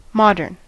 modern: Wikimedia Commons US English Pronunciations
En-us-modern.WAV